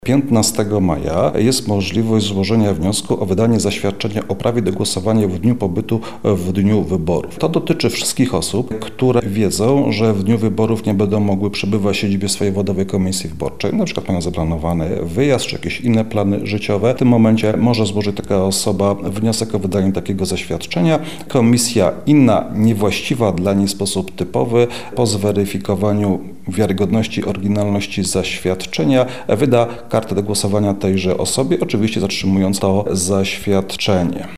O całej procedurze mówi sędzia Krzysztof Niezgoda, przewodniczący Okręgowej Komisji Wyborczej w Lublinie.